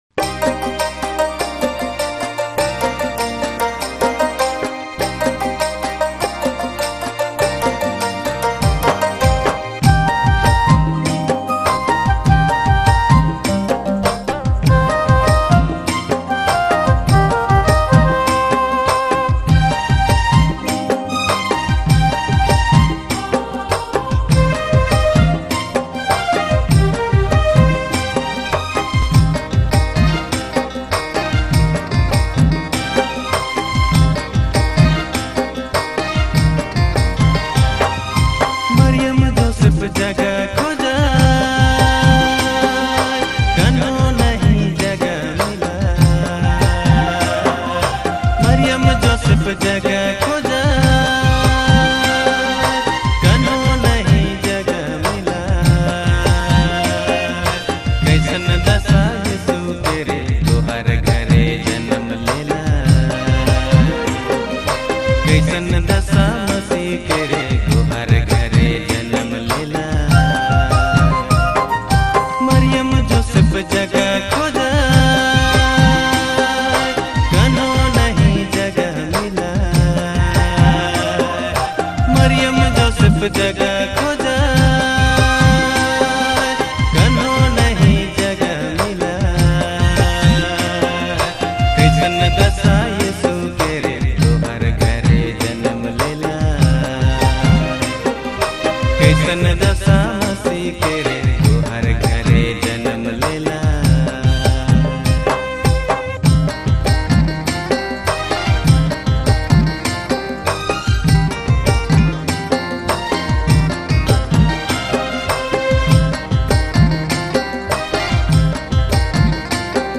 Dj Remixer
New Latest Christmas Song